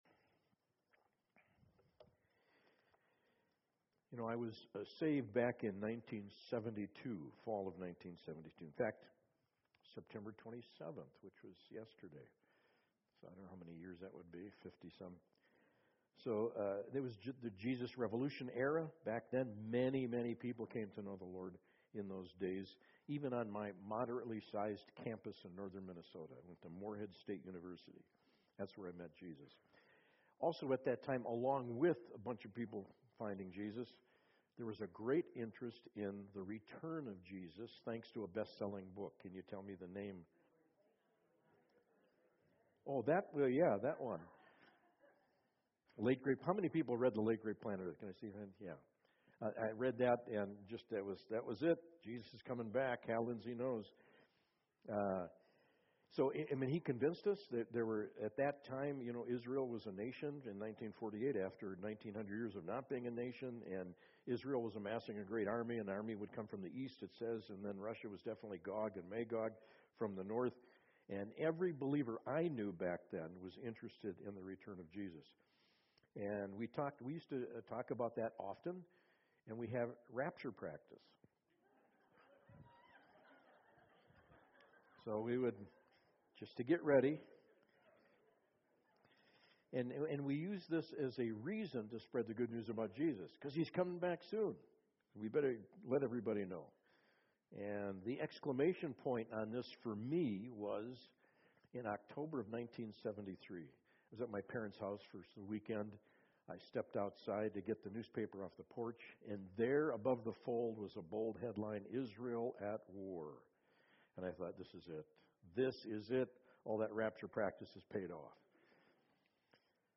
SERMONS Revelation Series # 9 “The Return of Jesus” A Series in Revelation September 28